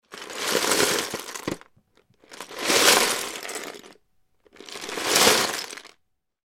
Звуки лего
Вываливаем лего из коробки на пол